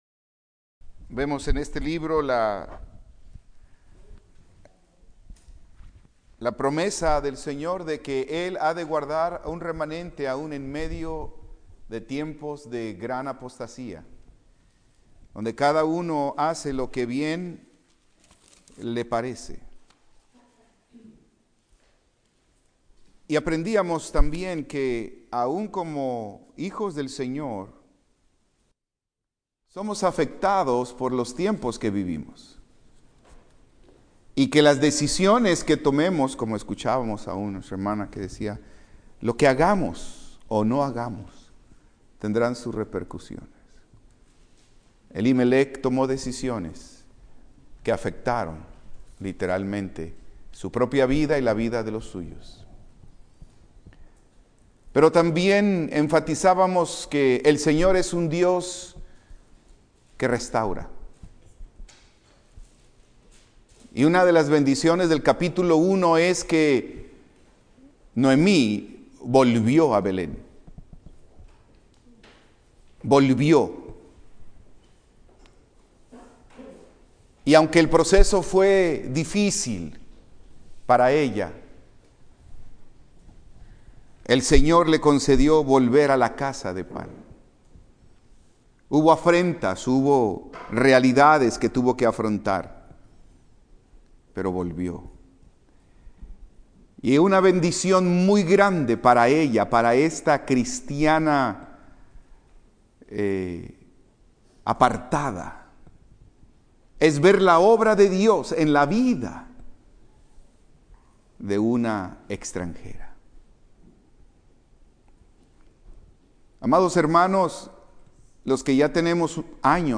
Servicio especial